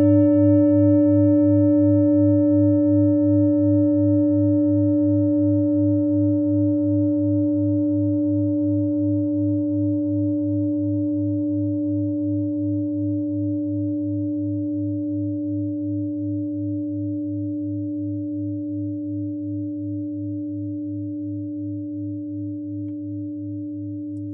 Klangschale Nepal Nr.54
(Ermittelt mit dem Filzklöppel)
Der Uranuston liegt bei 207,36 Hz und ist die 39. Oktave der Umlauffrequenz des Uranus um die Sonne. Er liegt innerhalb unserer Tonleiter nahe beim "Gis".
klangschale-nepal-54.wav